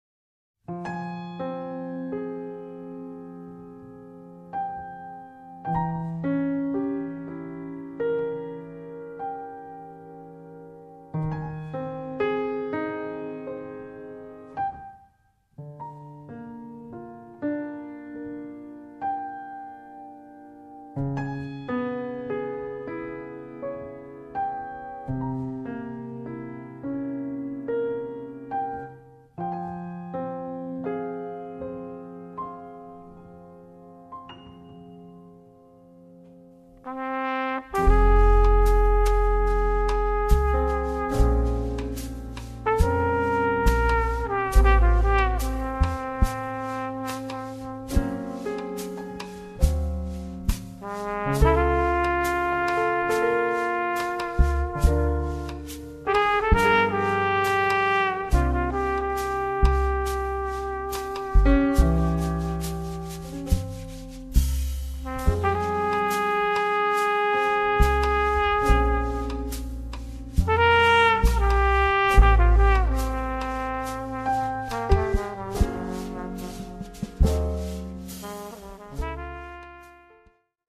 tromba e flicorno
sax tenore, alto e soprano
pianoforte
basso elettrico
batteria
ballad-bolero